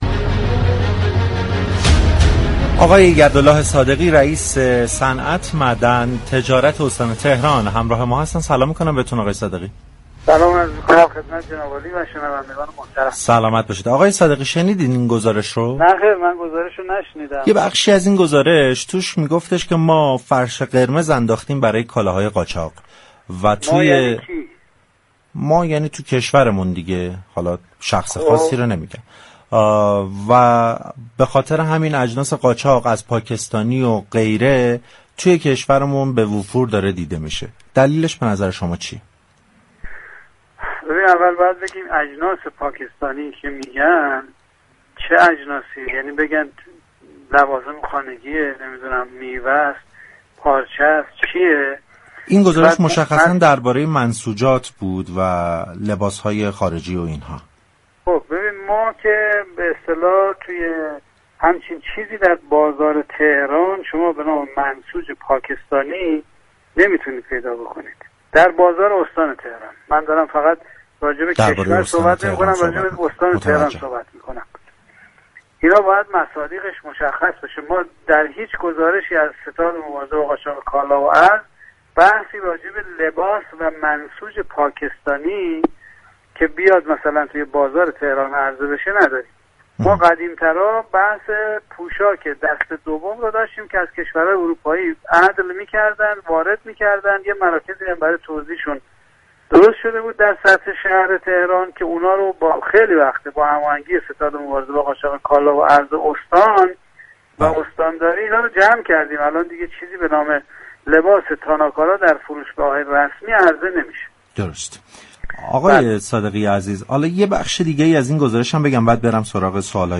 به گزارش پایگاه اطلاع رسانی رادیو تهران، یدالله صادقی رئیس سازمان صنعت معدن و تجارت استان تهران، در گفتگو با برنامه "بازار تهران" با تكذیب فروش اجناس مختلف به ویژه منسوجات و لباس های خارجی پاكستانی در بازارهای این استان اظهار داشت: در هیچ یك از گزارش های ستاد مبارزه با قاچاق كالا و ارز، گزارشی مربوط به عرضه لباس و منسوجات پاكستانی در بازار تهران نداریم.